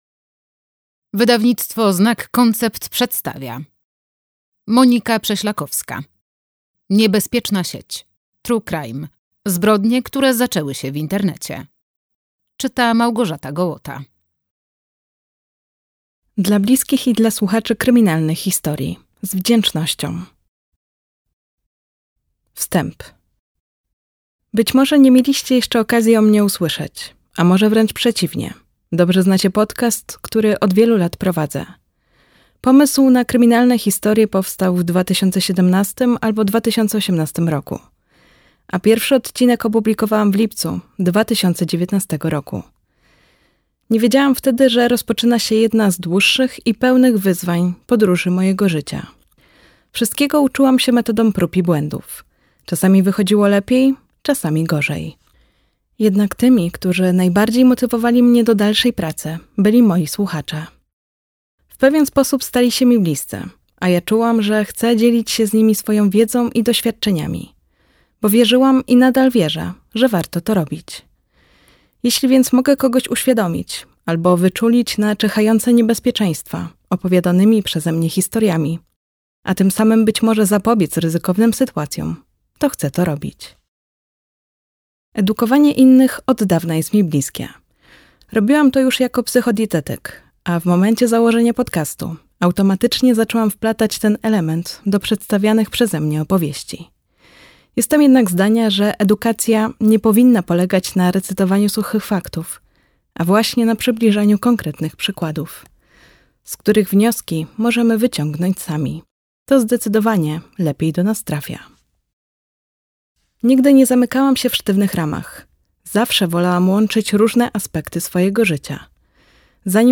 audiobook + książka